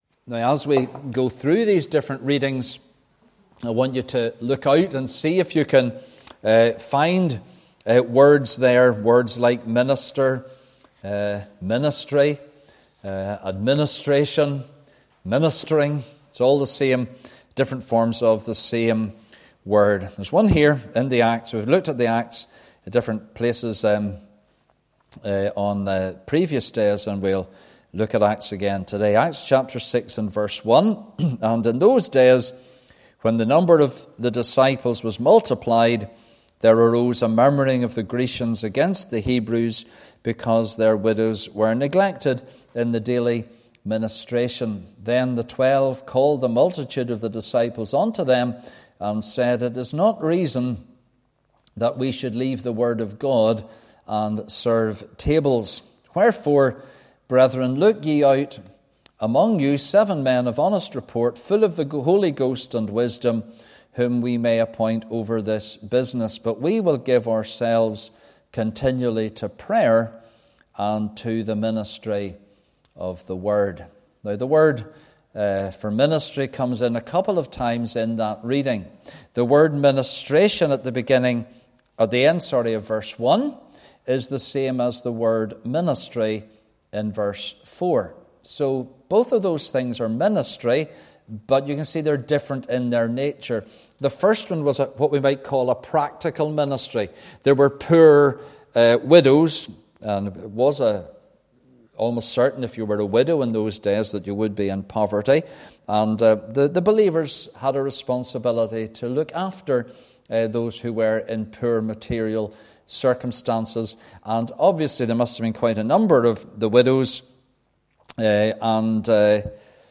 (Message preached Sunday 28th January)